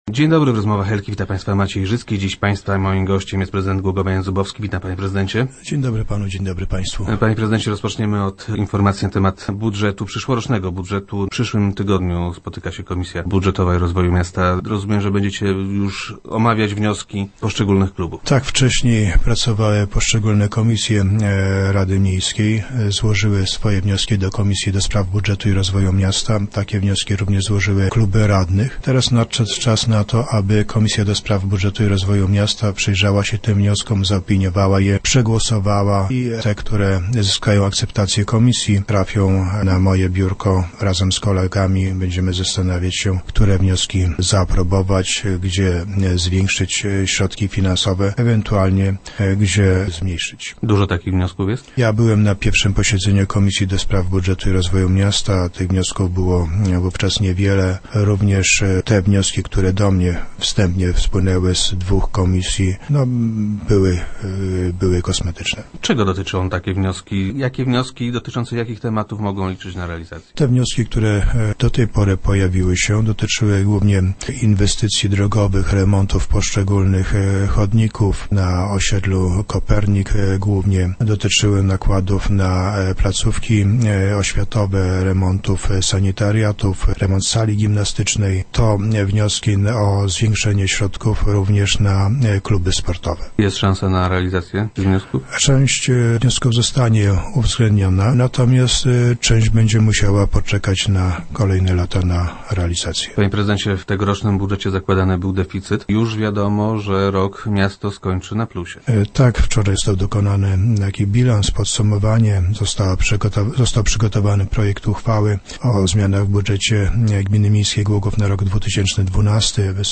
- Zamiast zaplanowanego w budżecie deficytu w wysokości czternastu milionów złotych, będzie nadwyżka - informuje Jan Zubowski, prezydent Głogowa, który był gościem Rozmów Elki.
- W tej chwili ta nadwyżka jest poniżej miliona złotych i wynosi około 880 tysięcy. Sądzę jednak, że do końca roku będzie nieco większe wykonanie dochodów, nieco mniejsze wykonanie wydatków i prawdopodobnie nadwyżka wyniesie około 2,5 miliona złotych - mówił na radiowej antenie prezydent Zubowski.